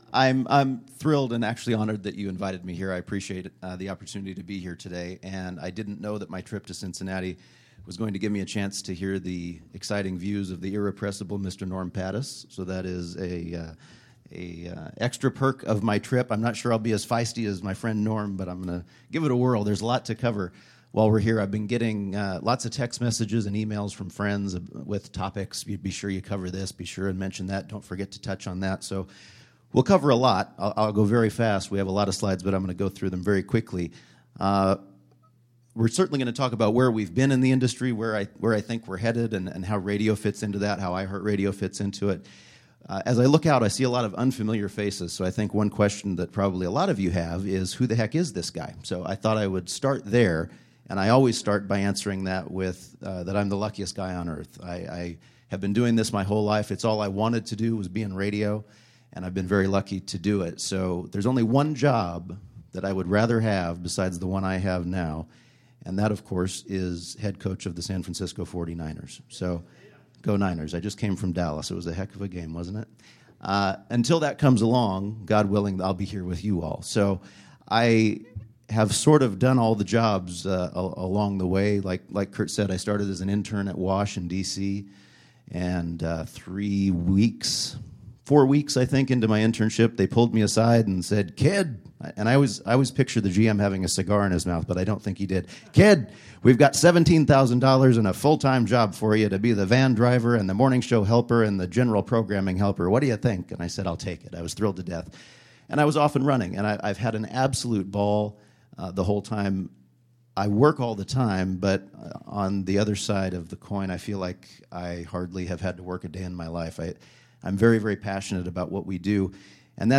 Keynote Speech